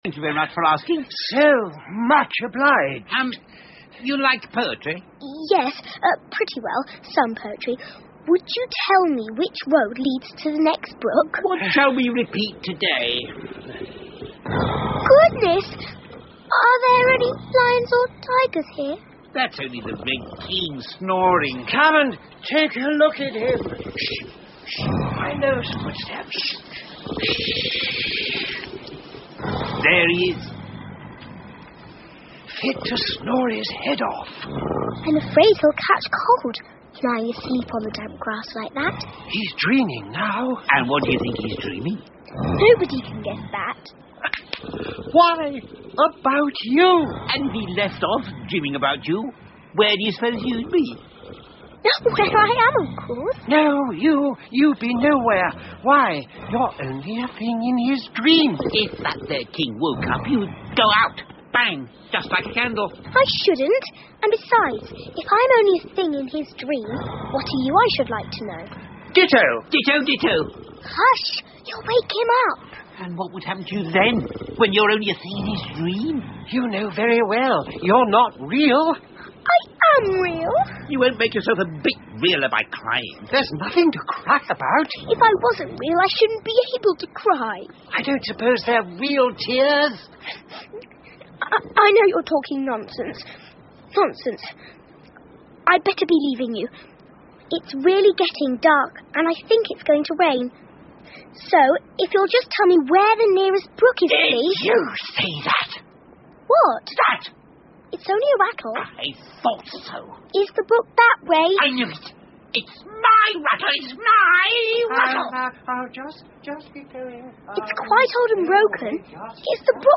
Through The Looking Glas 艾丽丝镜中奇遇记 儿童广播剧 11 听力文件下载—在线英语听力室